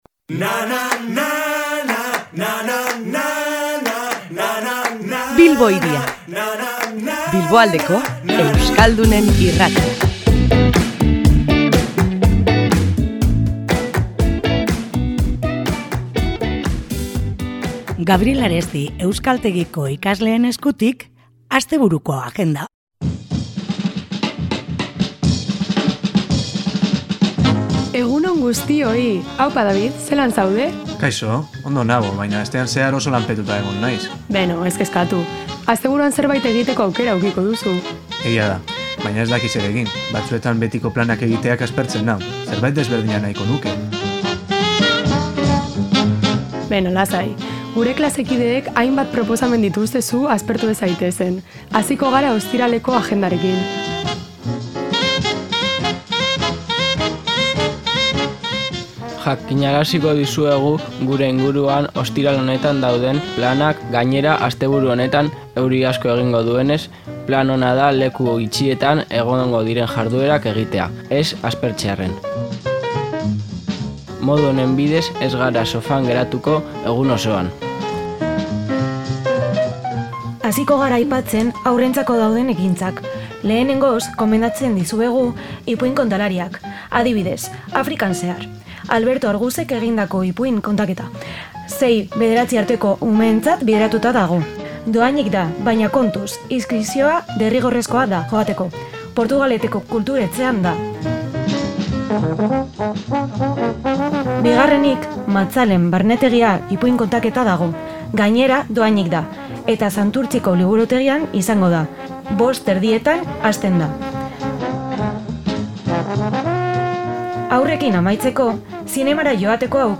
Asteburua ate joka dugu, eta ohikoa denez, agendari begirada bat botatzeko unea iritsi da. Gaur, Gabriel Aresti euskaltegiko ikasleak izan ditugu Bilbo Hiria irratian, eta haiek ekarritako proposamenekin astebururako plan erakargarriak ekarri dizkigute.